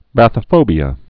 (băthə-fōbē-ə)